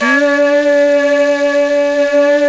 Index of /90_sSampleCDs/Roland LCDP04 Orchestral Winds/FLT_Jazz+Singin'/FLT_Singin'Flute